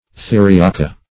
theriaca - definition of theriaca - synonyms, pronunciation, spelling from Free Dictionary
Theriac \The"ri*ac\, Theriaca \The*ri"a*ca\, n. [L. theriaca an